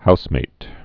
(housmāt)